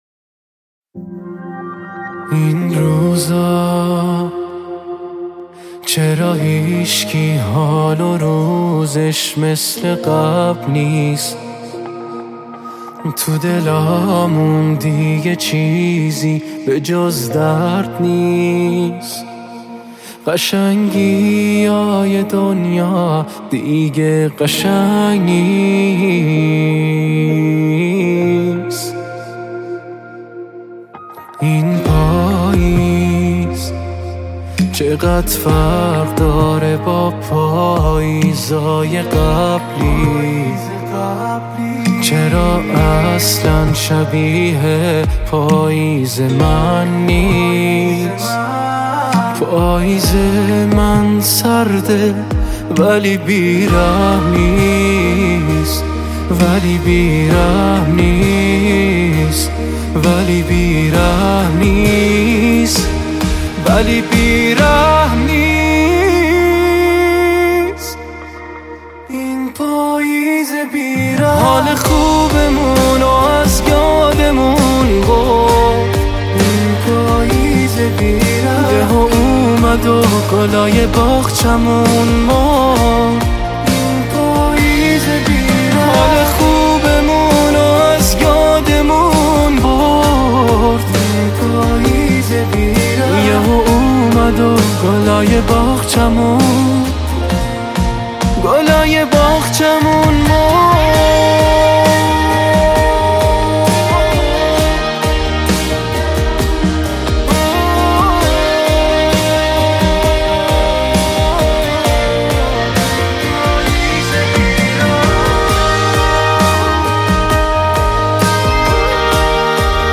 آهنگهای پاپ فارسی